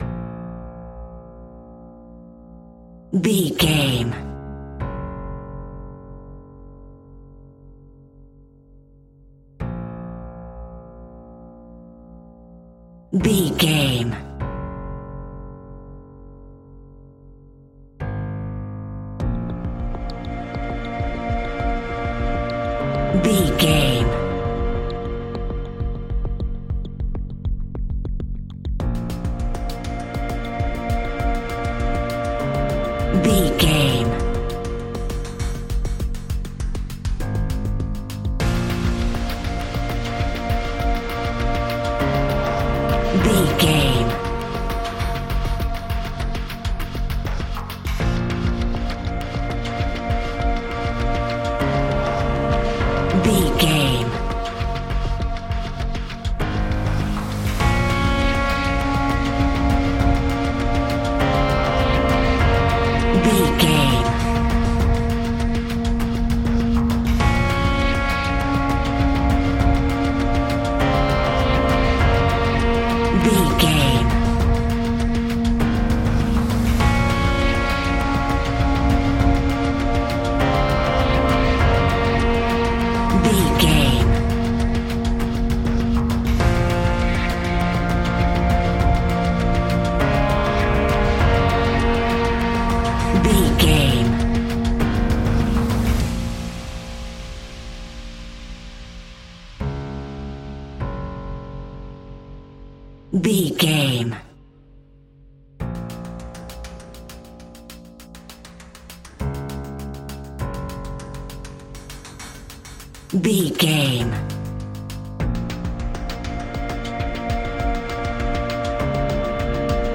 In-crescendo
Thriller
Aeolian/Minor
ominous
dark
haunting
eerie
horror music
Horror Pads
horror piano
Horror Synths